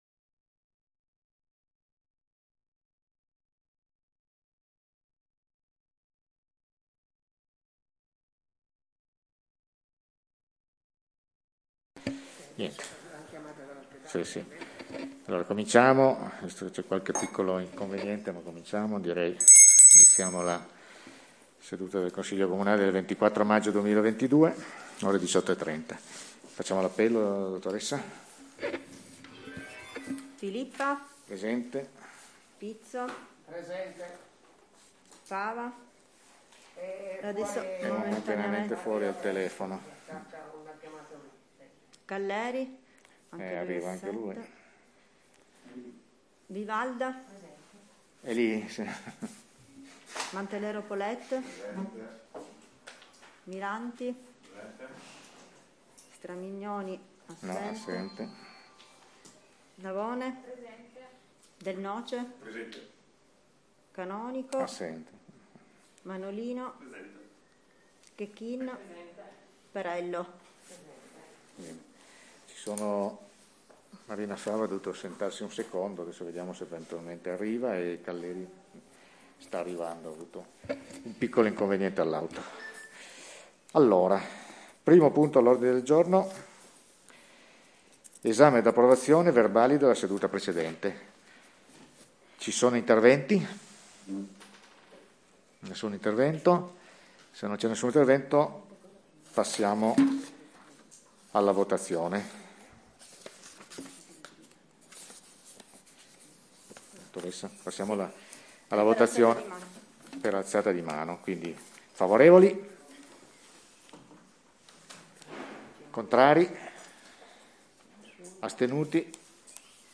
Registrazione Consiglio comunale - Comune di Pecetto Torinese